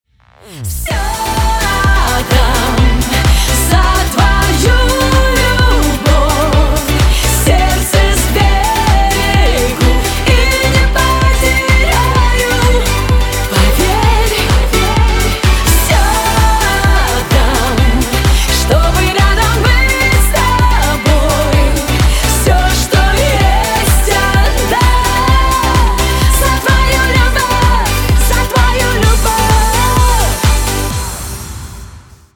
• Качество: 192, Stereo
попса
Долгожданная баллада